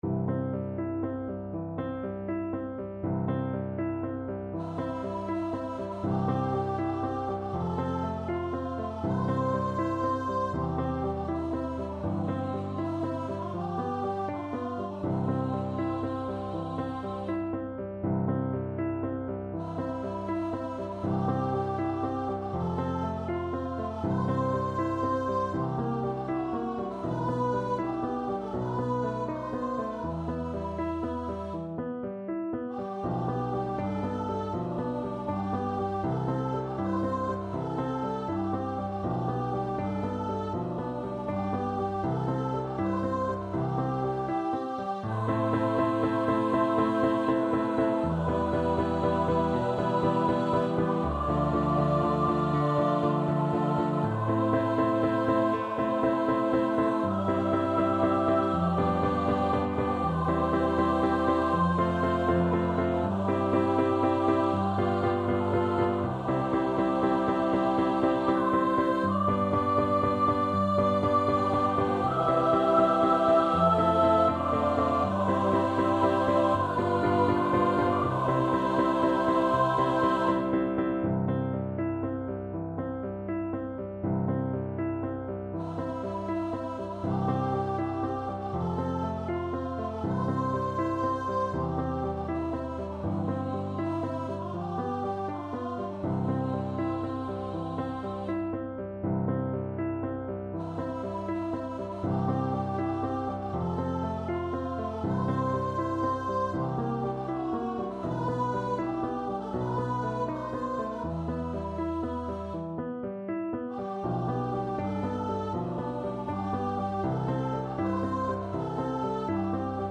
Free Sheet music for Choir (SAB)
Andante = c. 80
4/4 (View more 4/4 Music)
Choir  (View more Intermediate Choir Music)